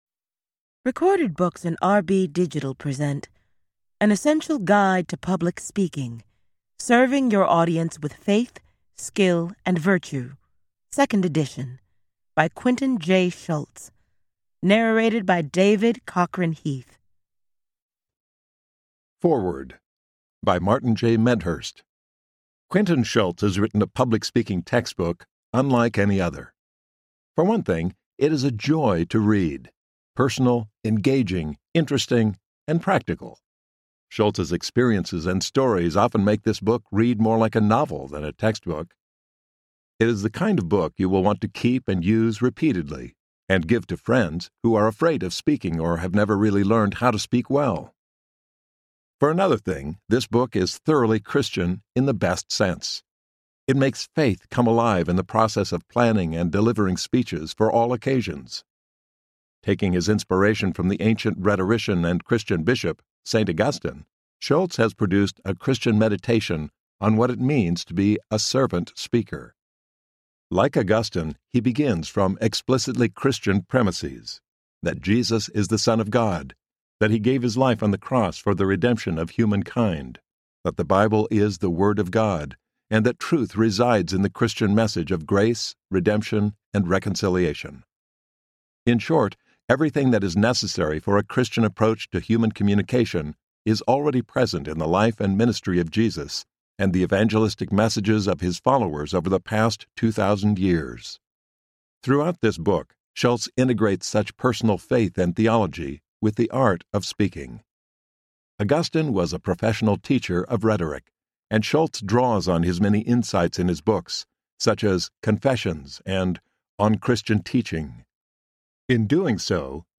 An Essential Guide to Public Speaking, 2nd edition Audiobook
Narrator
7.3 Hrs. – Unabridged